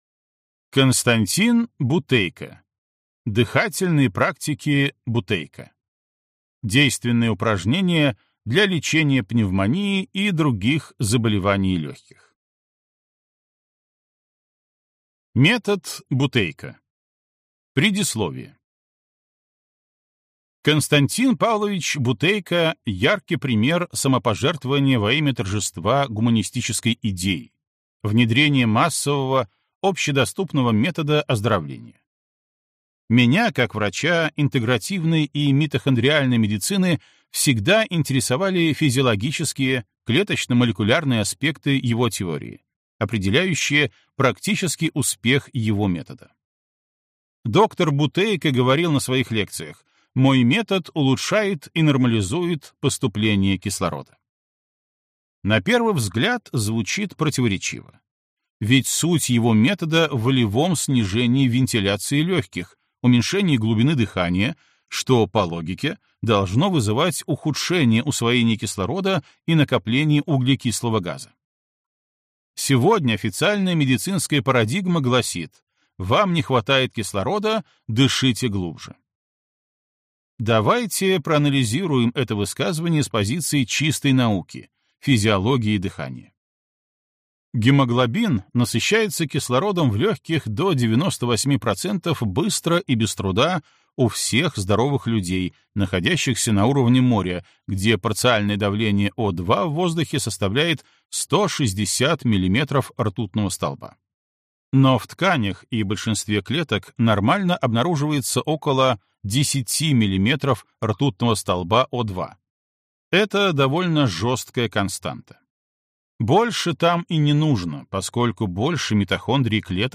Аудиокнига Дыхательные практики Бутейко | Библиотека аудиокниг